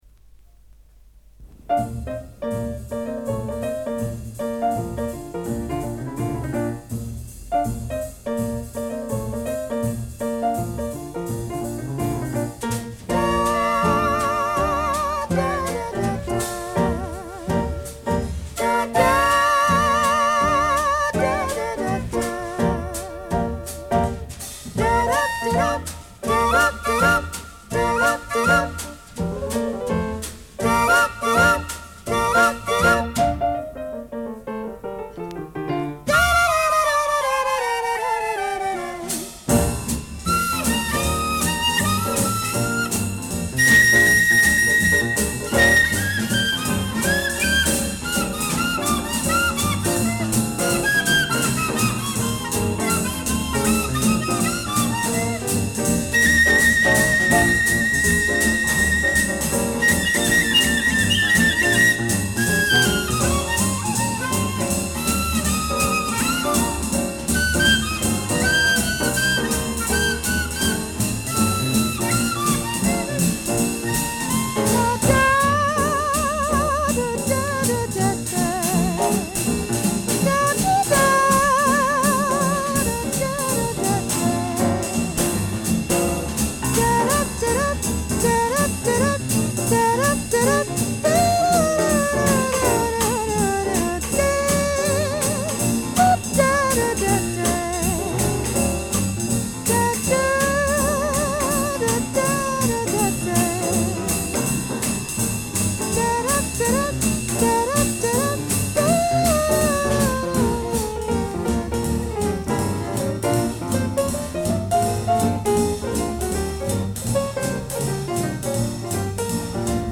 polished piano playing
a wordless vocal part
mono
pennywhistle